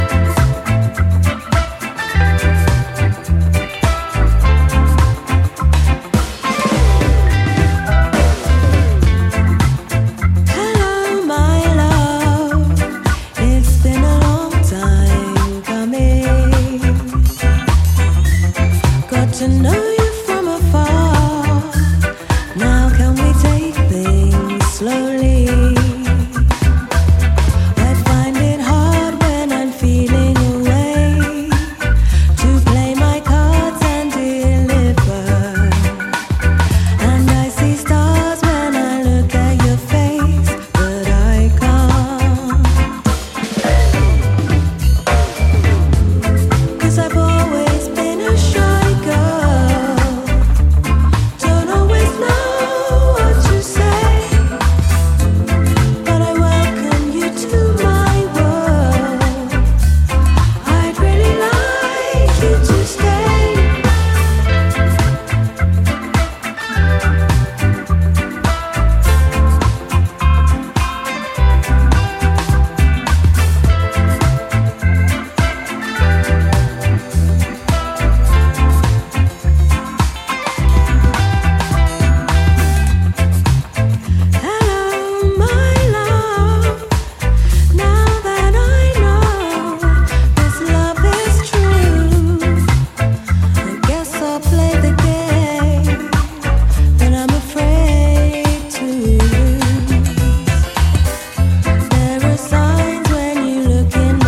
the anthemic, feel-good title track
buoyant and elastic slice of lovers rock
dreamy vocals radiate warmth and tenderness in equal measure